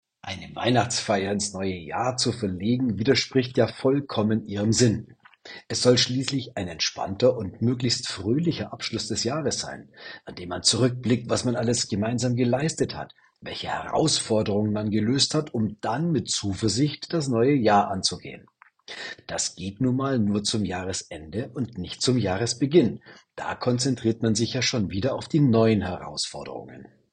Er ist ehemaliger Leistungssportler, Mental- und Kommunikationscoach.